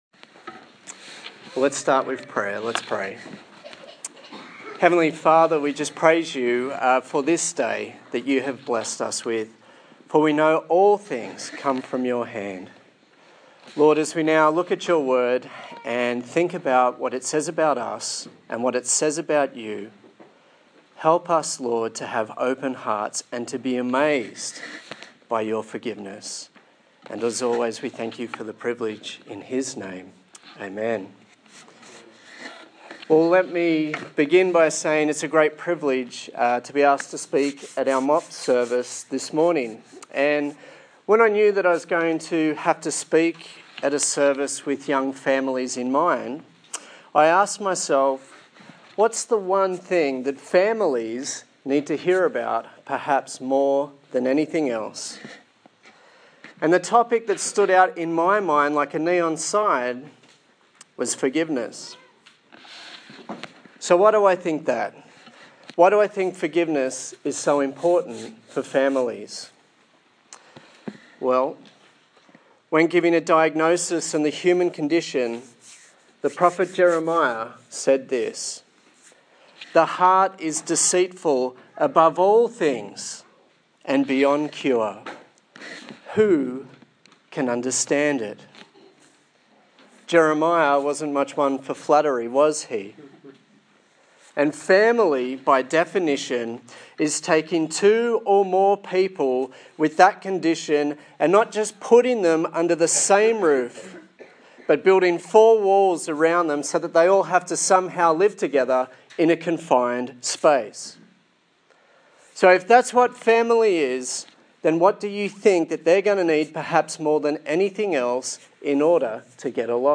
Matthew Passage: Matthew 18:21-35 Service Type: Sunday Morning